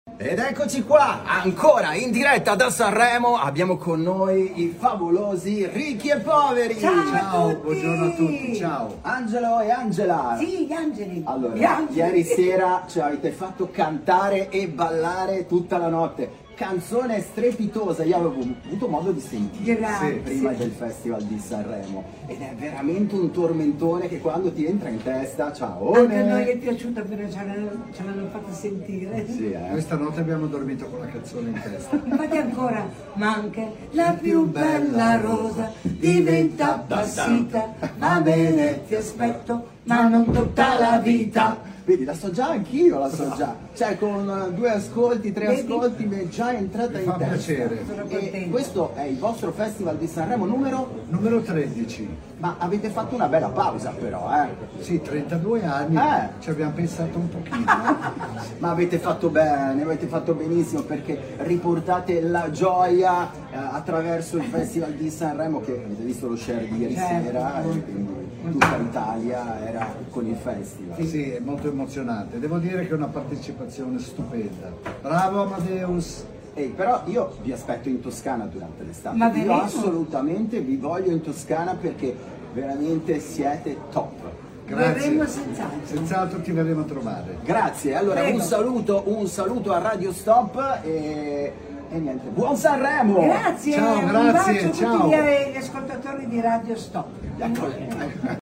Festival di Sanremo con Radio Stop!
Radio Stop – Intervista a I RICCHI E POVERI
Intervista-a-I-RICCHI-E-POVERI.mp3